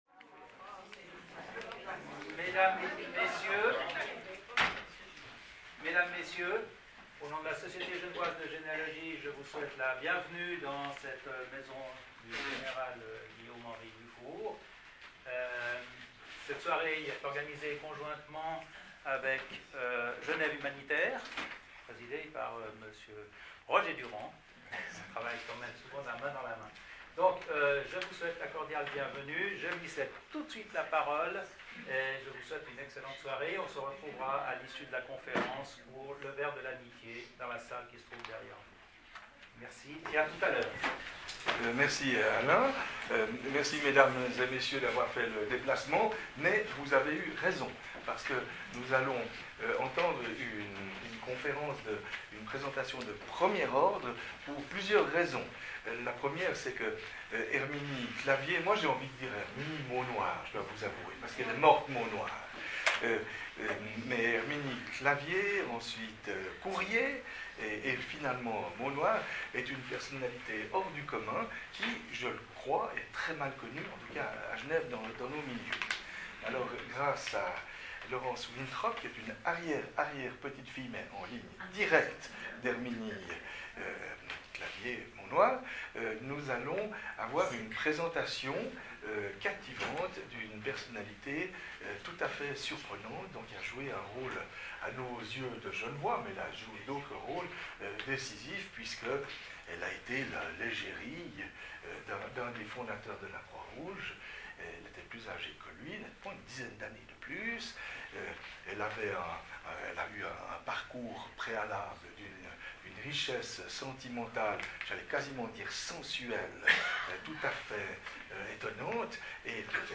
Ecoutez la conf�rence Enregistrement r�alis� le jeudi 19 f�vrier 2015 � la maison g�n�ral Dufour Please credit